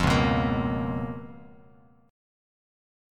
Db13 chord